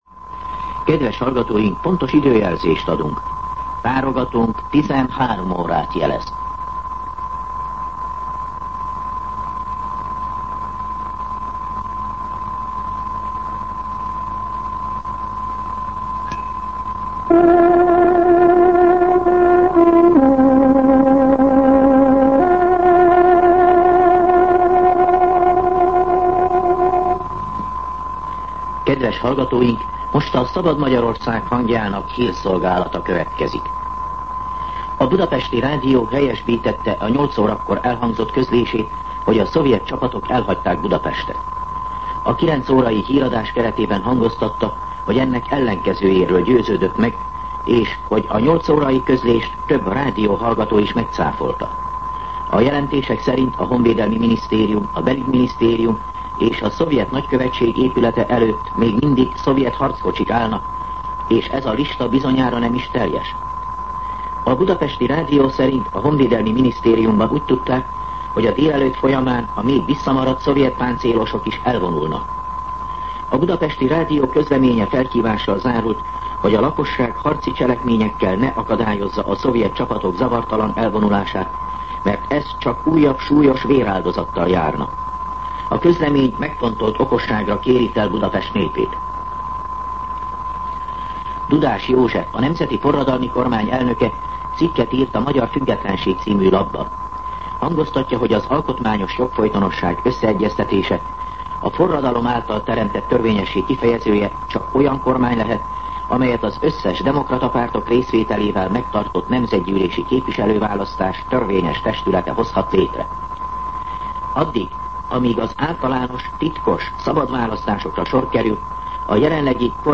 13:00 óra. Hírszolgálat